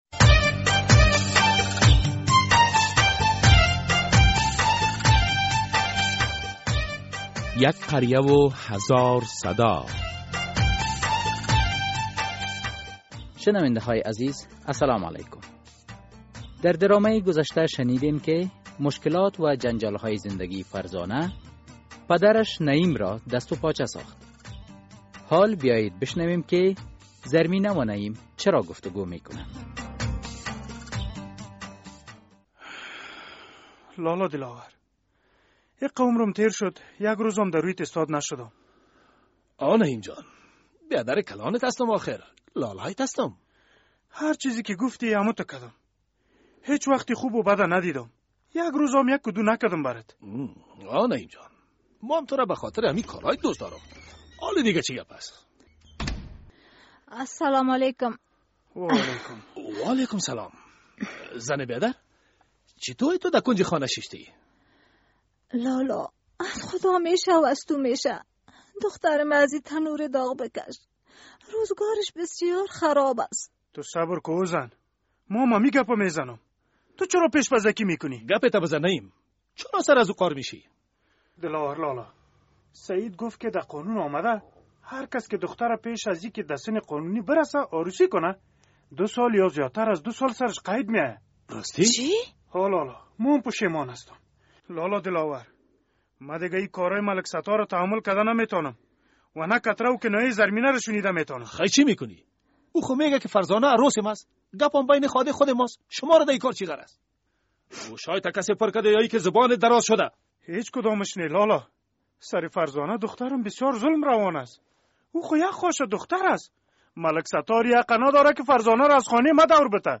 در این درامه که موضوعات مختلف مدنی، دینی، اخلاقی، اجتماعی و حقوقی بیان می‌گردد هر هفته به روز های دوشنبه ساعت ۰۳:۳۰ عصر از رادیو آزادی نشر می‌گردد...